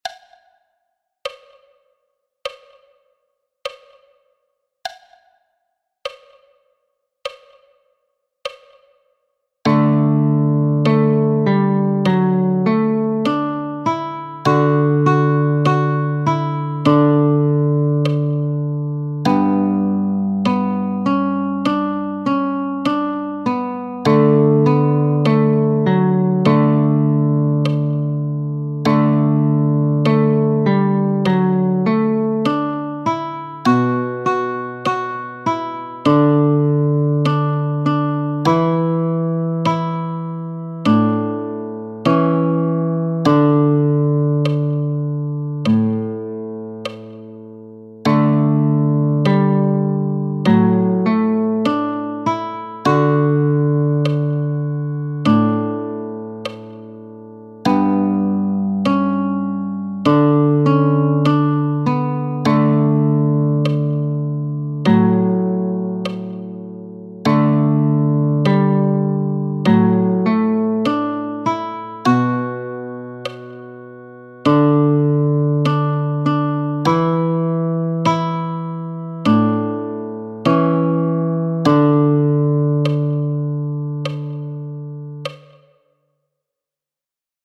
Liederbuch mit 35 einfachen Arrangements für die Gitarre.